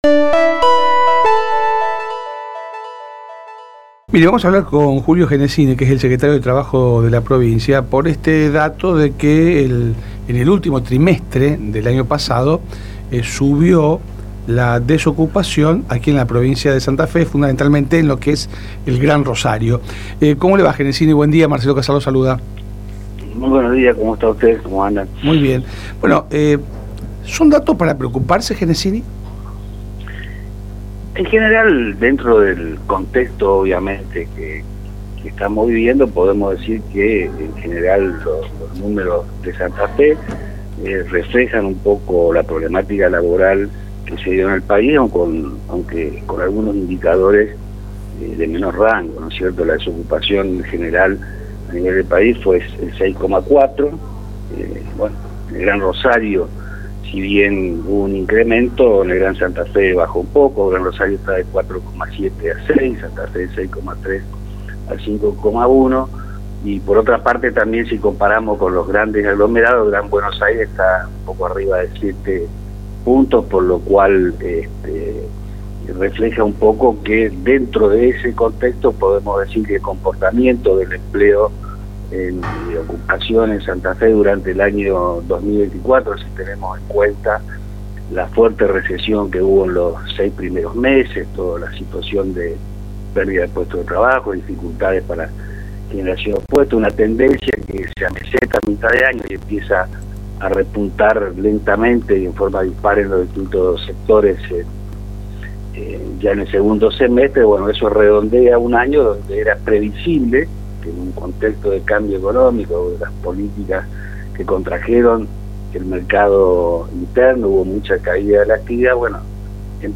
El secretario de trabajo de la Provincia de Santa Fe, Julio Genesini analizó por LT3 los datos de desempleo publicados por Instituto Nacional de Estadísticas y Censo (Indec) del último trimestre del año pasado en Santa Fe. Según los últimos datos publicados, en la zona del gran Rosario el desempleo pasó de 4,7% al 6%, mientras que en en el gran Santa Fe se registró un descenso del 6,3% al 5%.